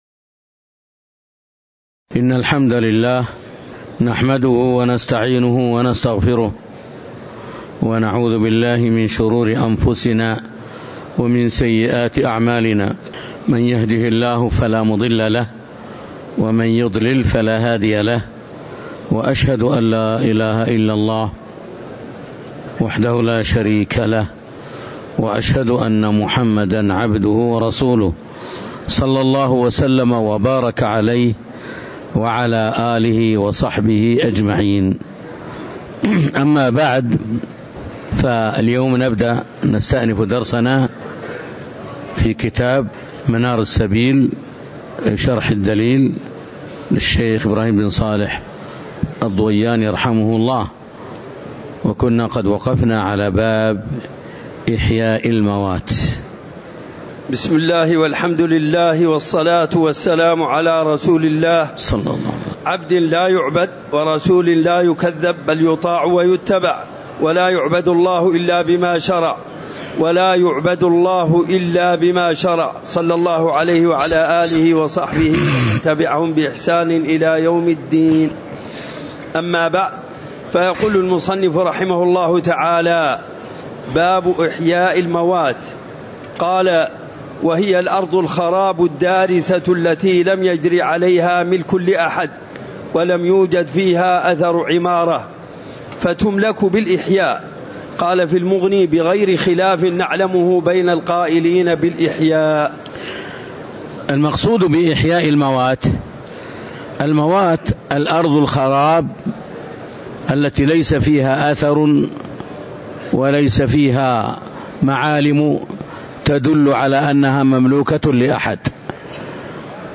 تاريخ النشر ٢٠ ذو الحجة ١٤٤٣ هـ المكان: المسجد النبوي الشيخ